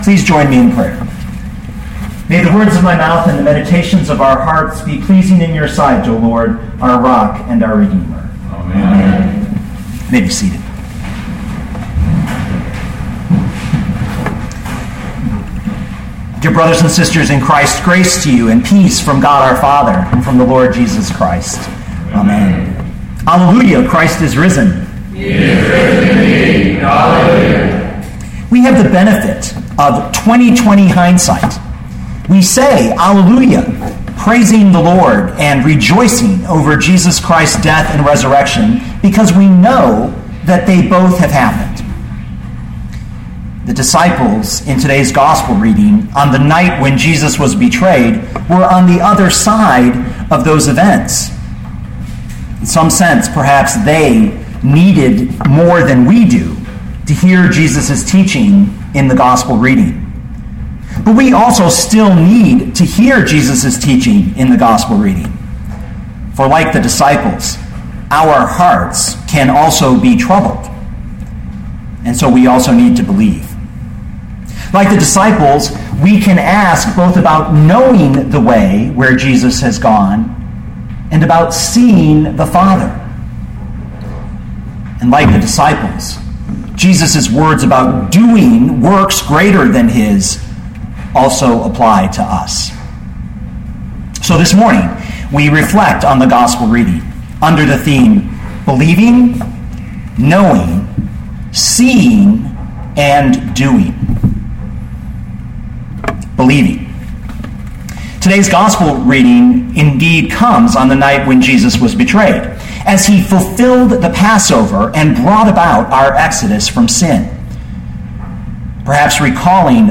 2014 John 14:1-14 Listen to the sermon with the player below, or, download the audio.